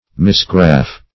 misgraff - definition of misgraff - synonyms, pronunciation, spelling from Free Dictionary Search Result for " misgraff" : The Collaborative International Dictionary of English v.0.48: Misgraff \Mis*graff"\, v. t. To misgraft.
misgraff.mp3